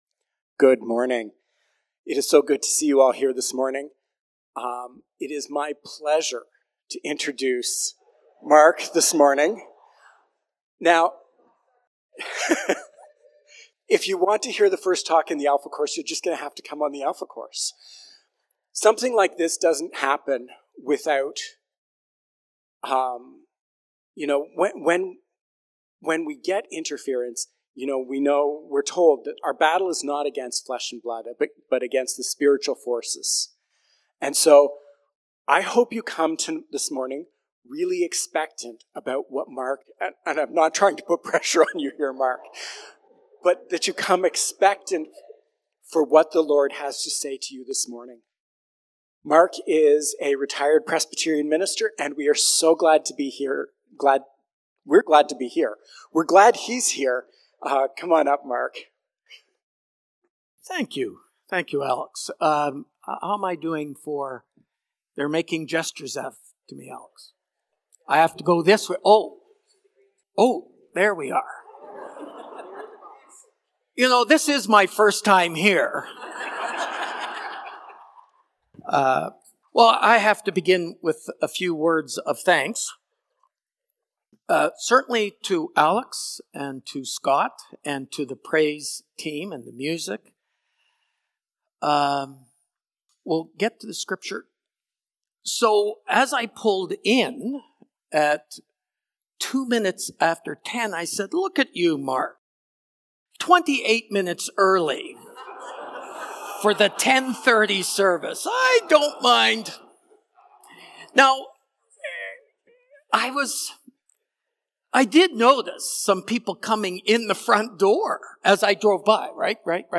March-15-Sermon.mp3